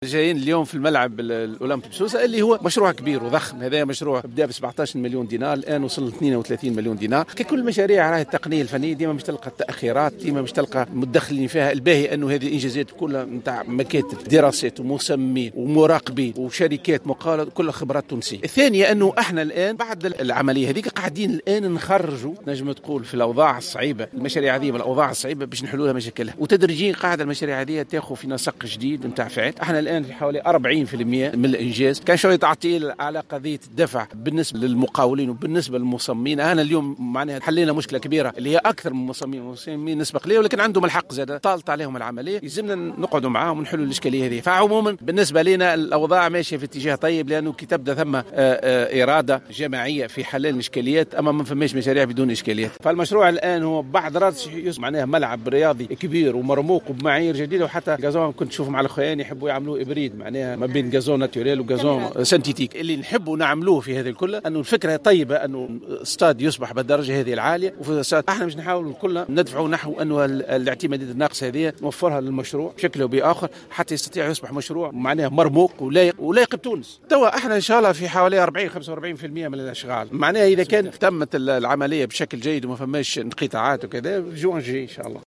وأكد السليطي، في تصريح للجوهرة أف أم على هامش الزيارة، أن نسبة تقدم المشروع وصلت إلى 40 %، مشيرا إلى تجاوز عديد الإشكاليات التي عطلت تقدمه، وخاصة مستحقات المقاول والمصممين، ومتوقعا إتمام الأشغال في شهر جوان 2021.